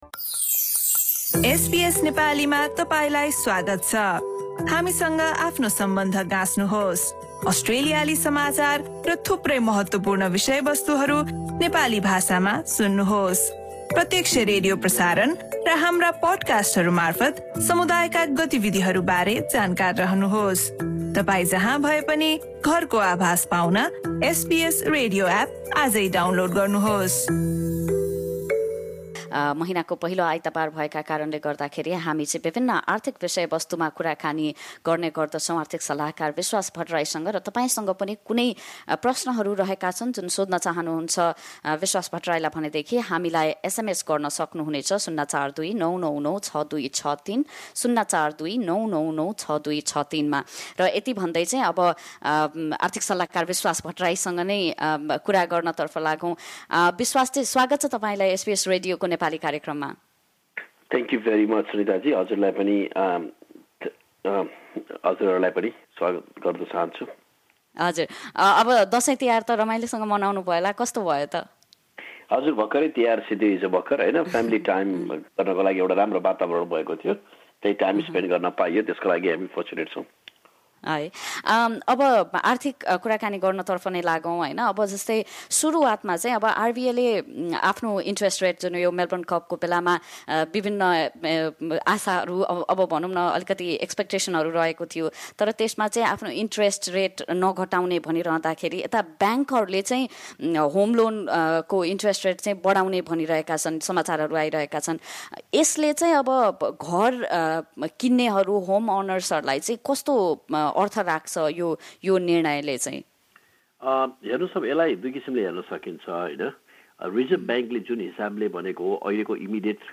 मासिक कुराकानी।